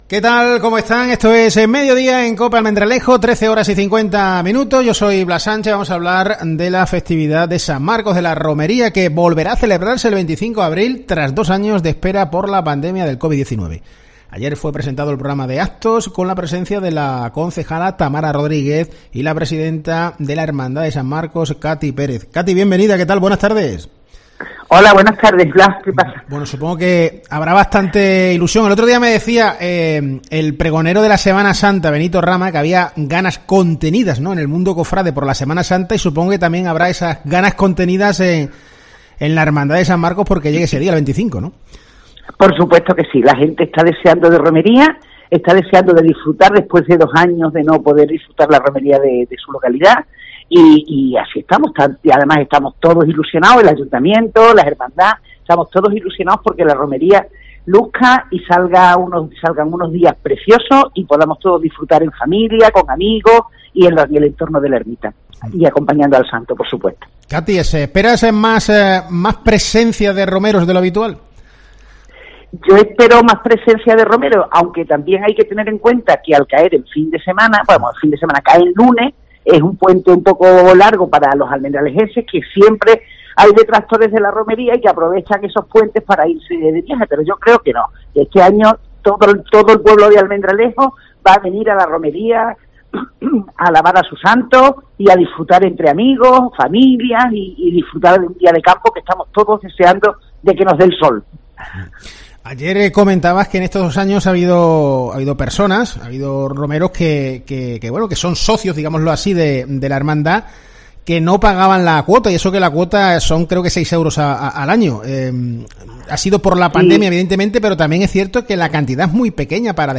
En COPE, hemos hablado con